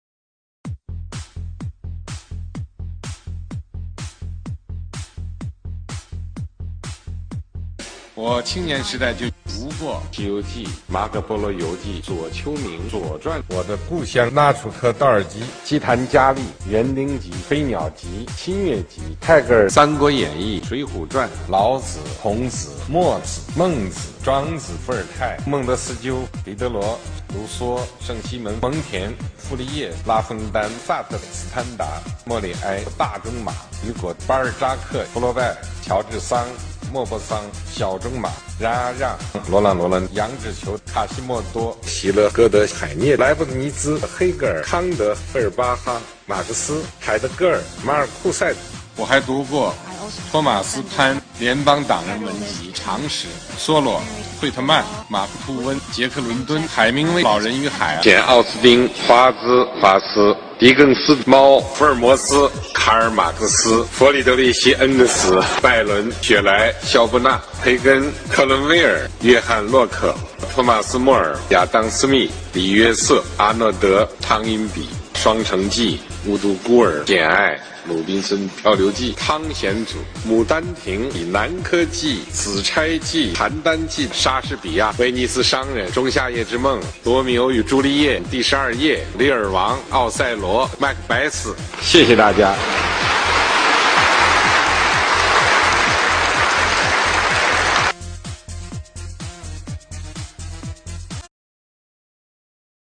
单口相声：习包子背书单.mp3